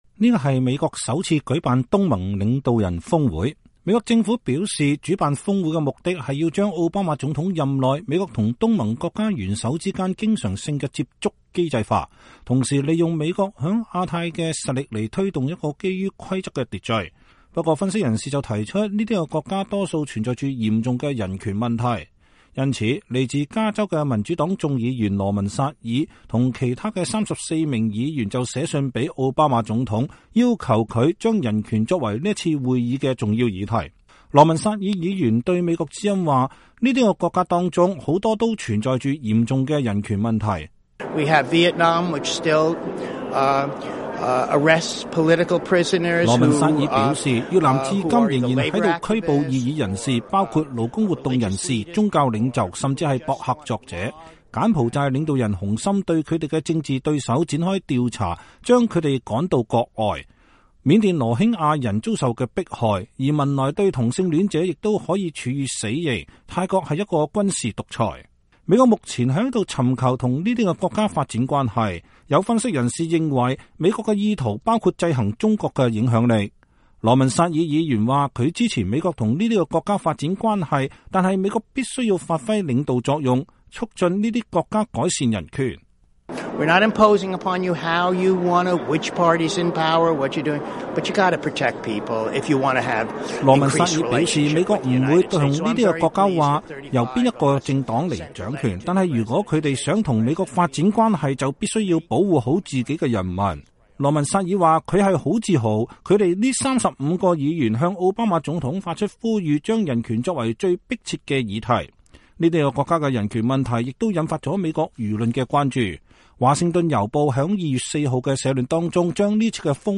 加州的民主黨眾議員羅文薩爾接受美國之音訪問。